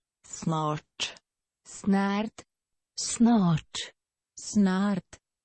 File File history File usage Samska_snart.ogg (file size: 51 KB, MIME type: application/ogg ) Prono guide to Samska snart File history Click on a date/time to view the file as it appeared at that time.